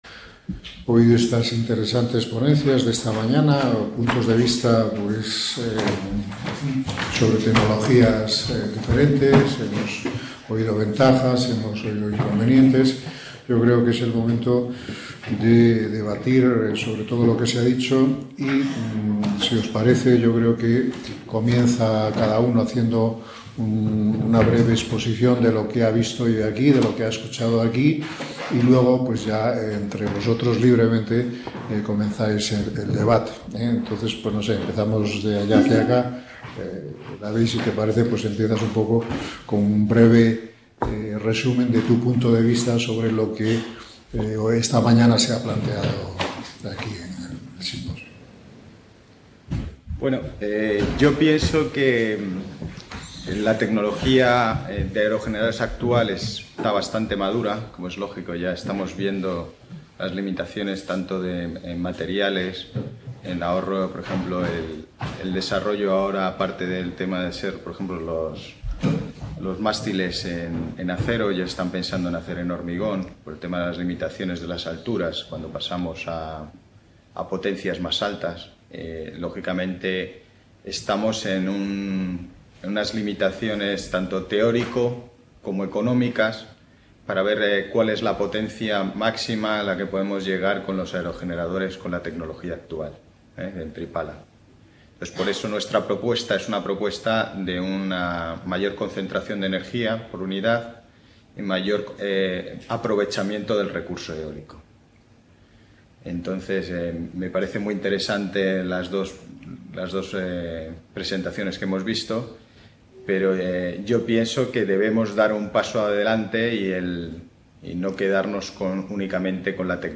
Mesa redonda - Recursos energéticos de la biomasa y…